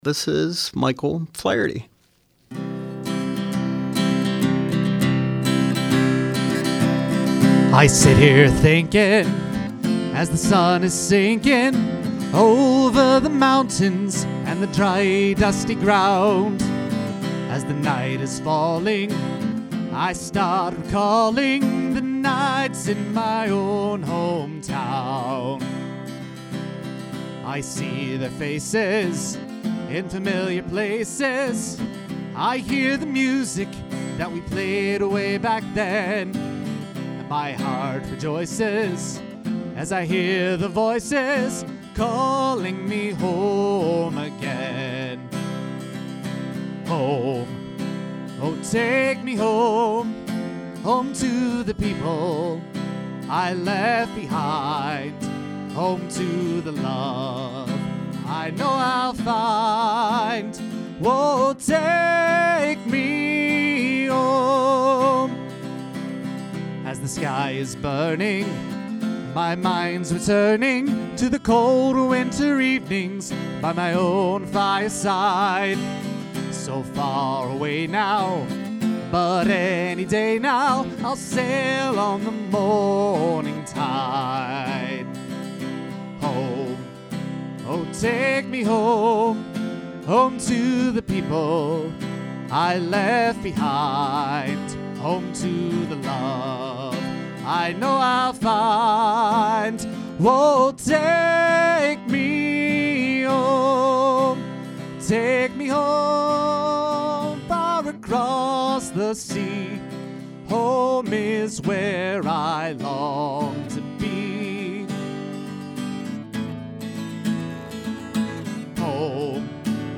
a blend of Celtic, American folk and country music.